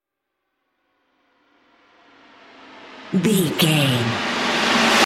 Atonal
E♭
drums
electric guitar
bass guitar
Sports Rock
hard rock
lead guitar
aggressive
energetic
intense
nu metal
alternative metal